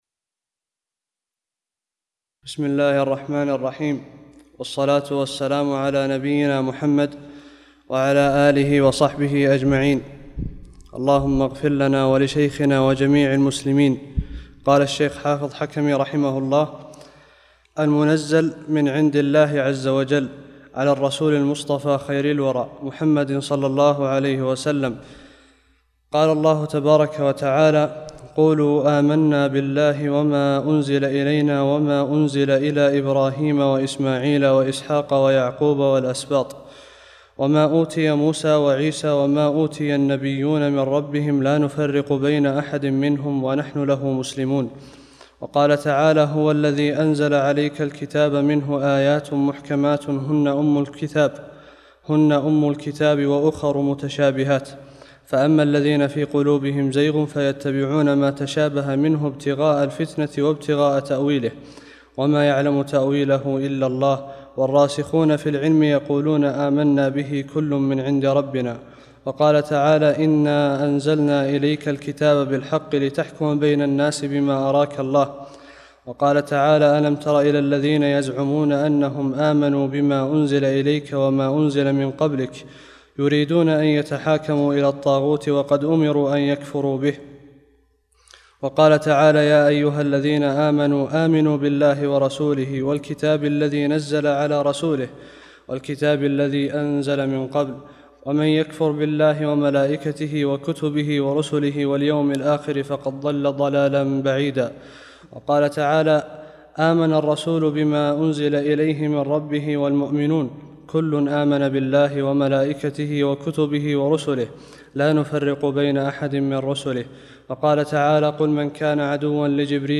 36 - الدرس السادس والثلاثون